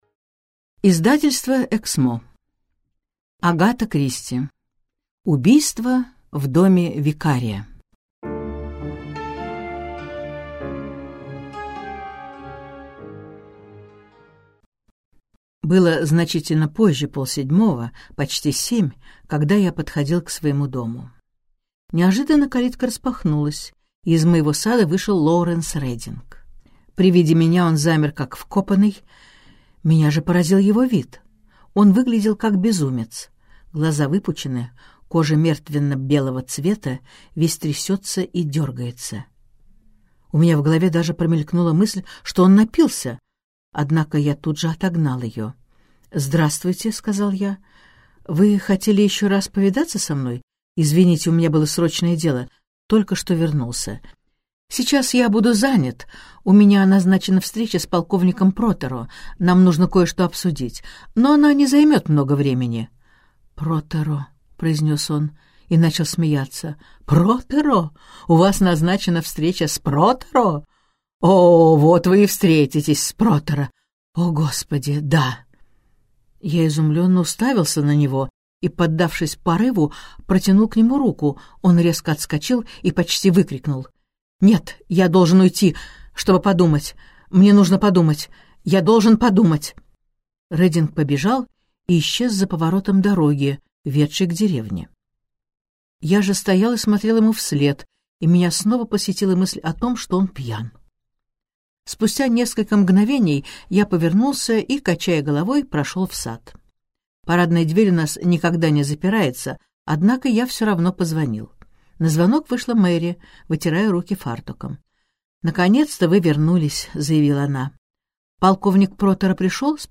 Аудиокнига Убийство в доме викария - купить, скачать и слушать онлайн | КнигоПоиск